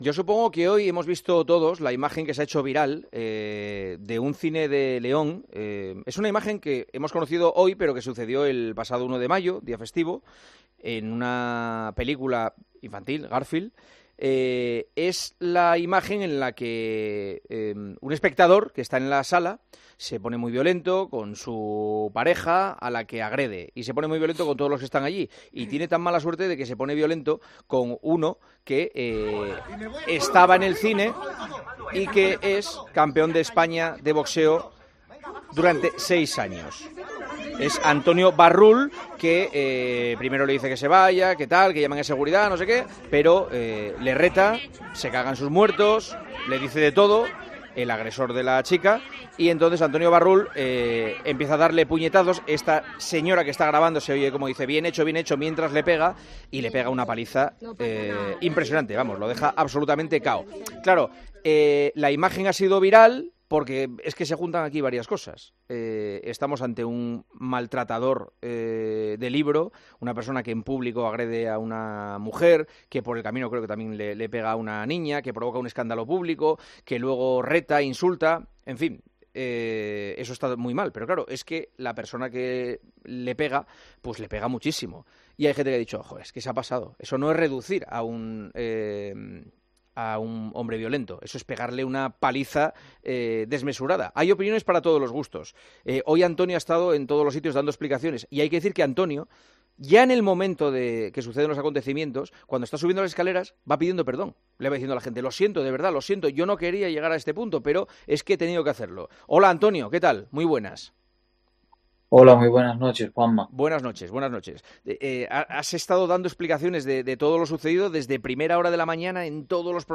ha pasado por los micrófonos de El Partidazo y ha explicado cómo se desarrolló...